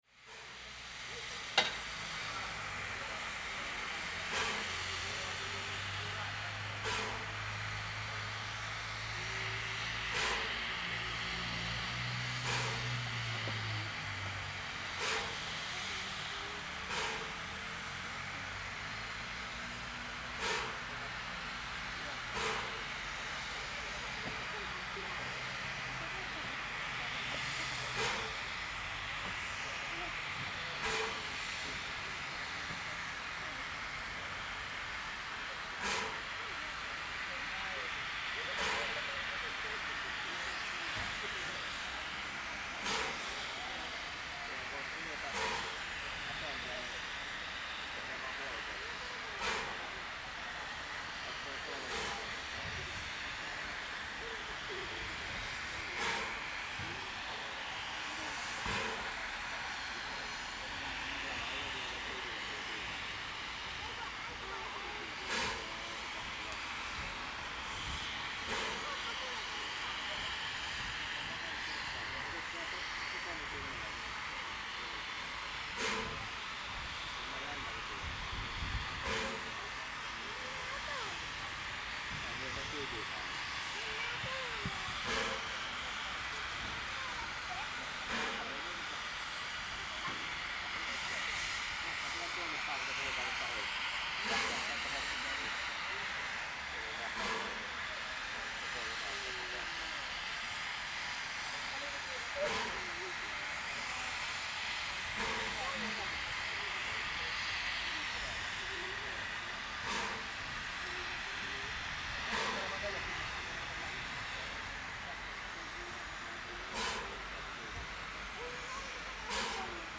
In the recording you can hear a museum train leaving from the platform in Äänekoski.
The train is pulled by two steam locomotives: Vr1 665 manufactured by Hanomag in 1921 and Tk3 1132 manufactured by Tampella in 1945.
• Steam Locomotive